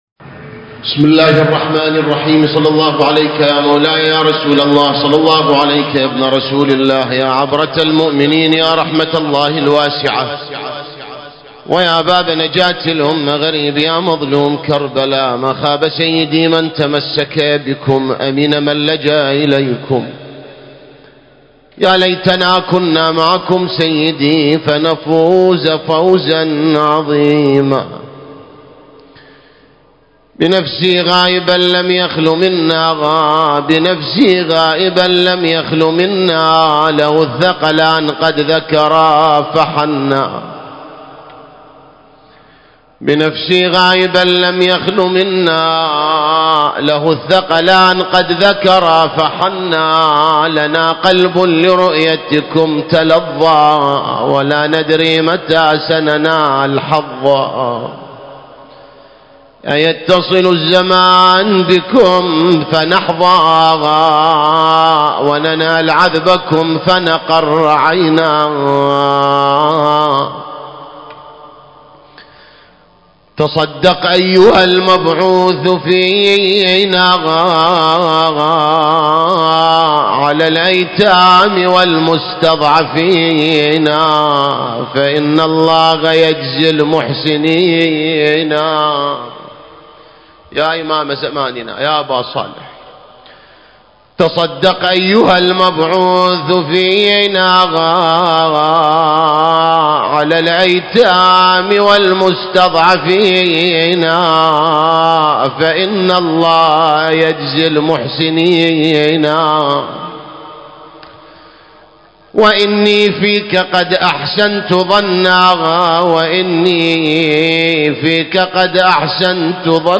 المكان: هيئة شباب الحوراء لإحياء الشعائر الحسينية - بغداد التاريخ: 2022